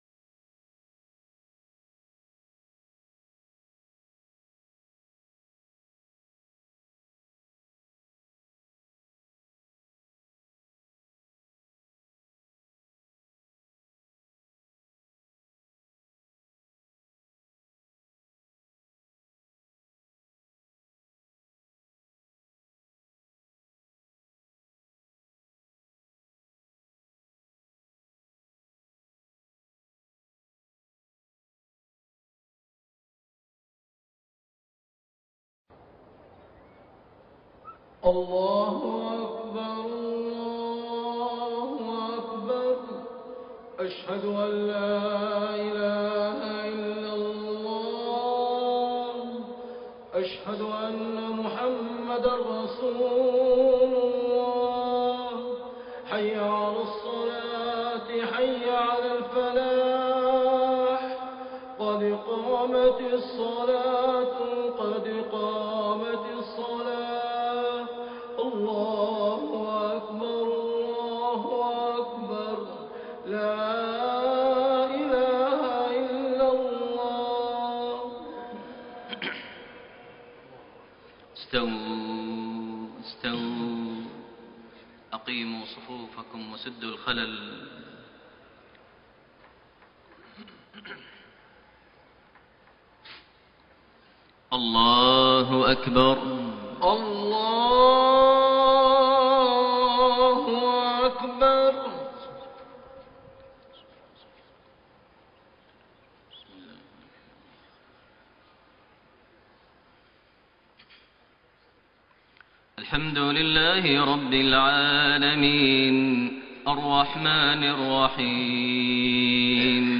Maghrib prayer from Surah Al-Ahzaab > 1433 H > Prayers - Maher Almuaiqly Recitations